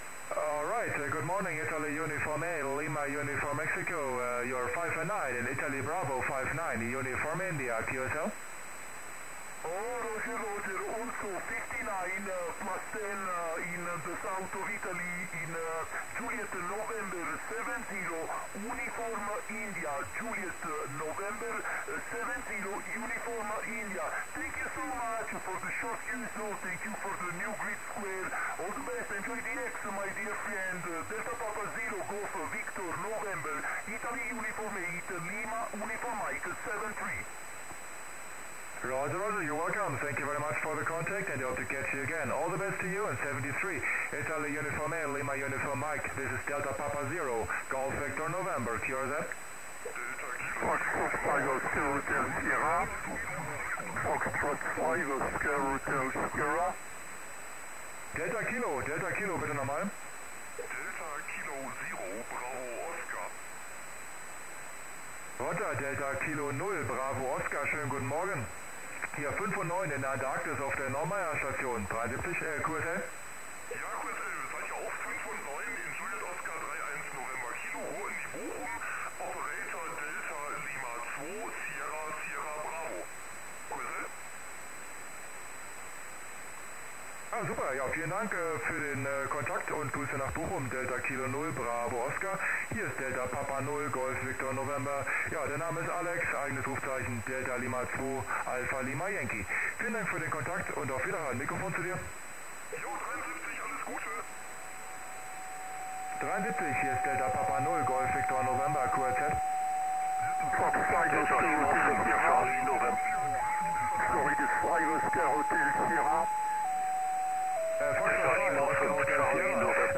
Прием велся в Виннице с геостационарного радиолюбительского спутника QO100.